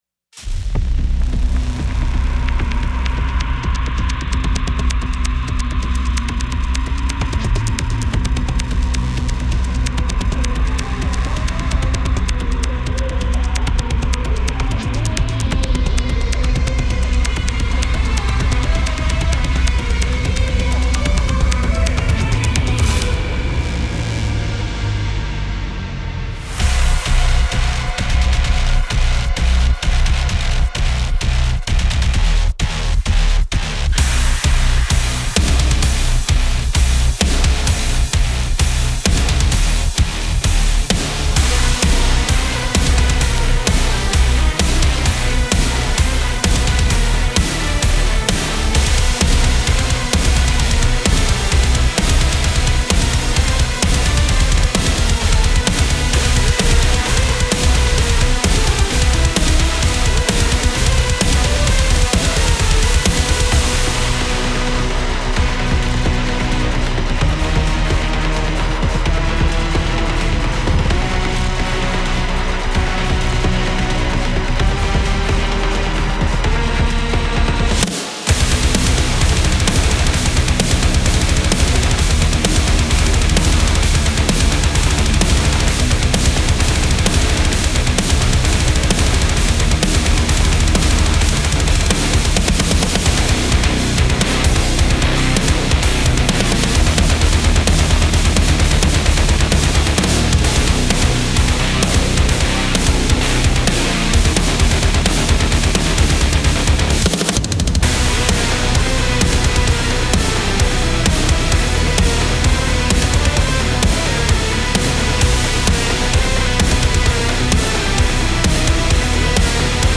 • トレーラー、サウンドトラック、スコアなどの強烈で生々しい感情表現に最適
• 300以上のサウンドソースから生まれた、ペダル、ベース、リード、モジュラーシンセの宝庫
容赦ないサウンド
荒々しくパワフルなベース、リード、リズムなど、激しいアクションに最適なサウンドを探求してください。